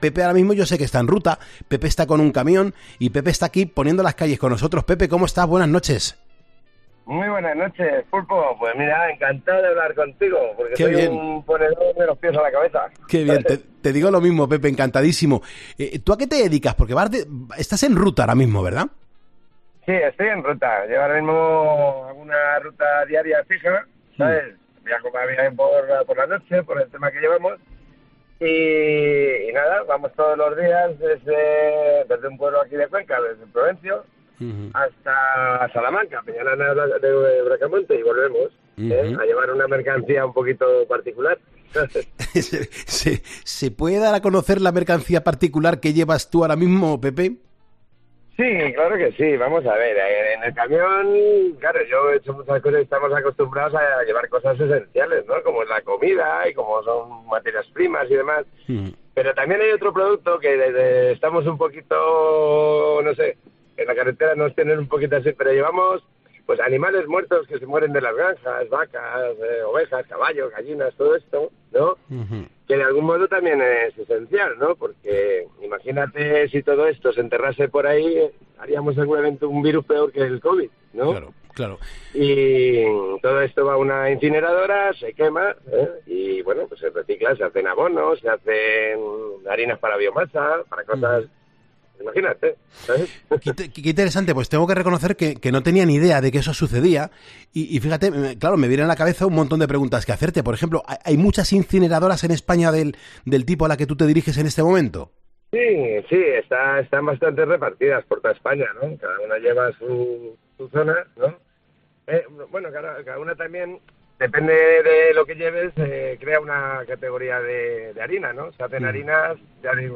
Un camionero